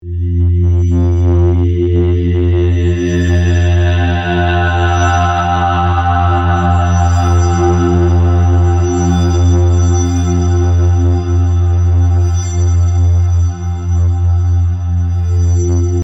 Spellcasting chant of Doren Hildrson